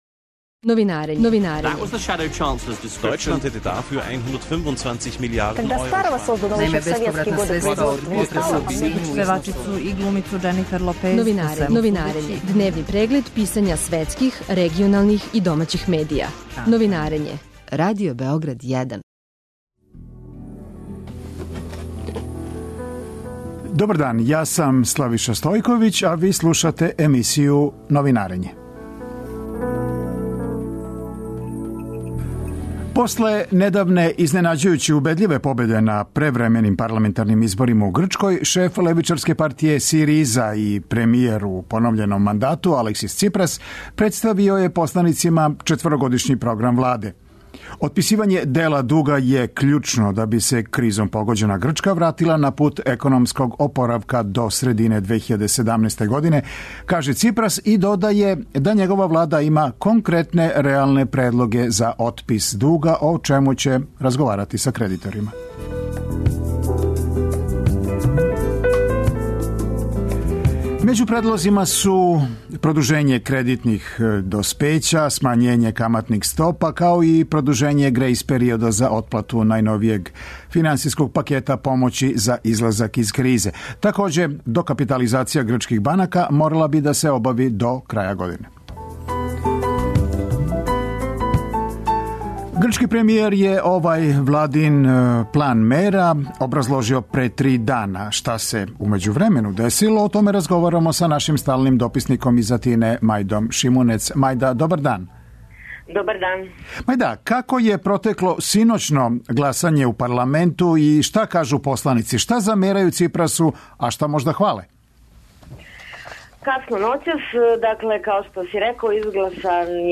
[ детаљније ] Све епизоде серијала Аудио подкаст Радио Београд 1 Ромска права у фокусу Брисела Дипломатски односи и позиционирање Србије у међународном поретку Вести из света спорта Хумористичка емисија Хумористичка емисија